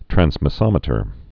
(trănsmĭ-sŏmĭ-tər, trănz-)